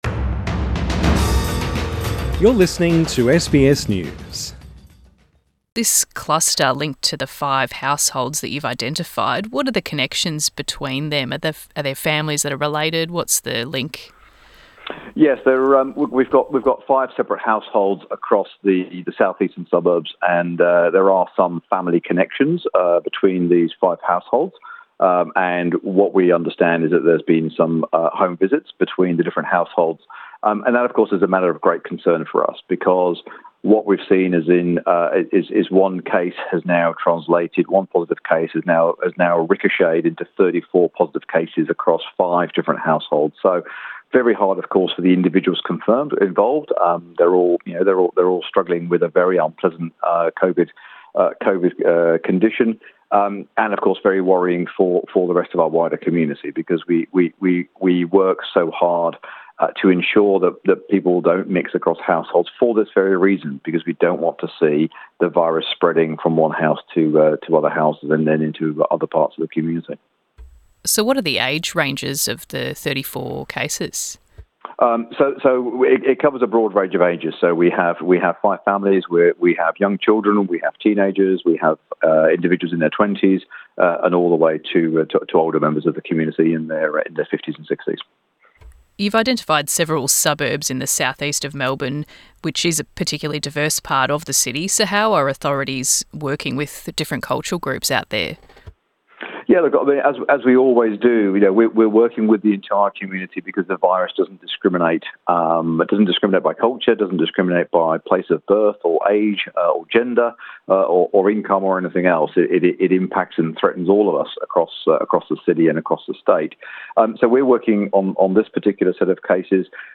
Interview with Jeroen Weimar, Commander of Victoria's COVID testing program